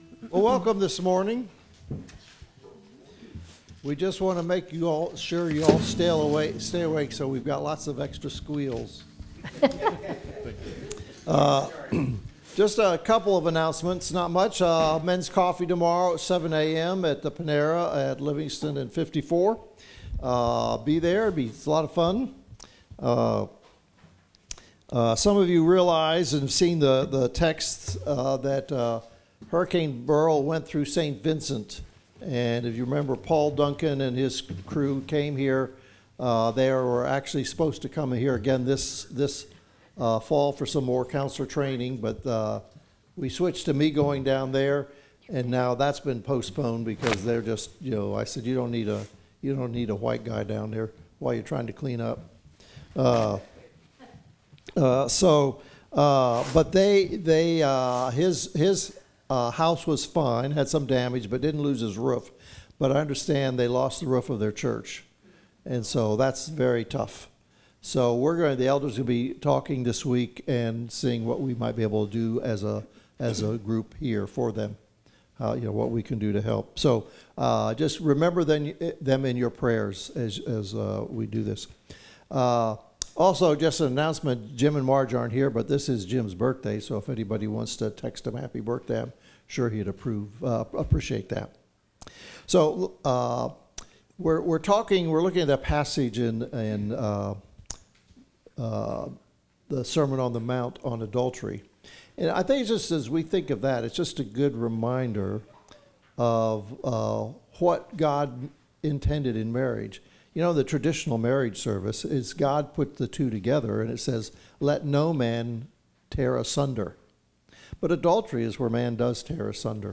Matthew 5:27-30 Service Type: Gathering As a youngster